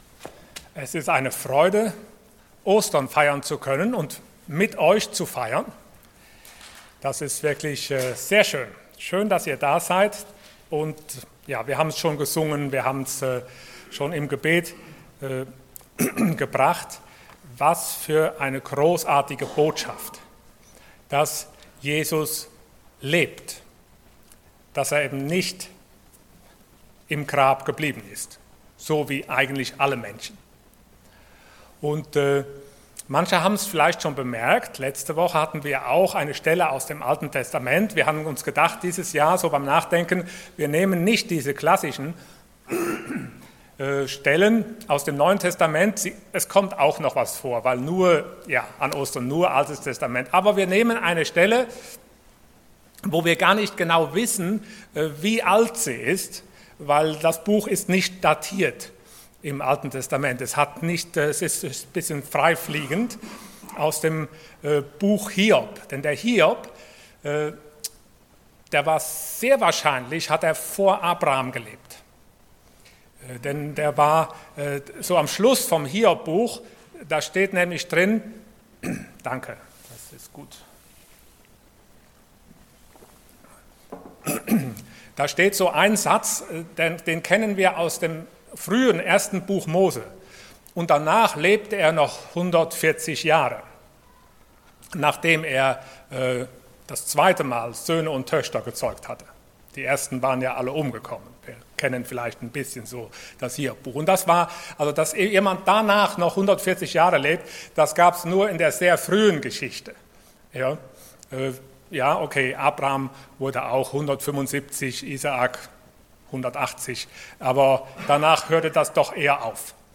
Passage: Job 19:23-27 Dienstart: Sonntag Morgen